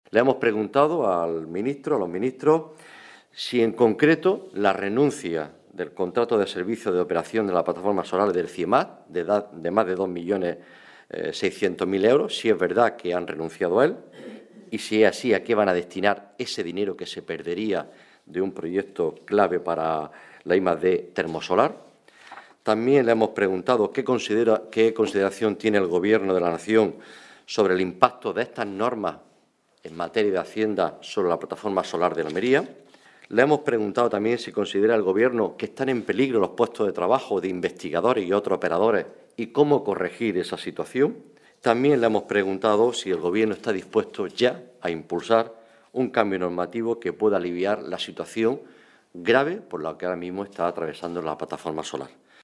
Rueda de prensa que han ofrecido el senador del PSOE de Almería, Juan Carlos Pérez Navas, y los diputados nacionales Sonia Ferrer y Juan Jiménez